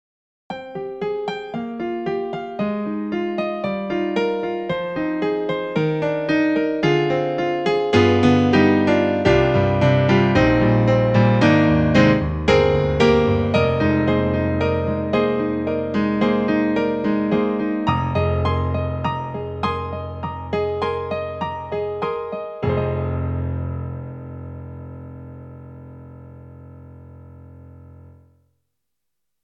特集：徹底比較！DTM音源ピアノ音色聴き比べ - S-studio2